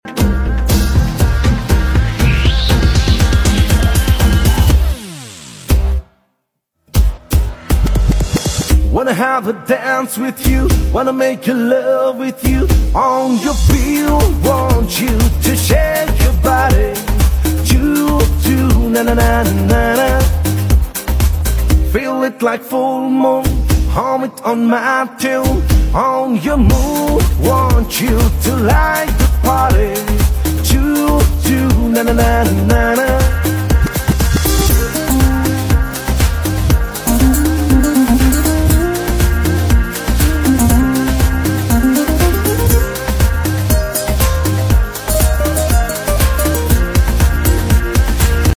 Traditional and modern Nepali songs sung in English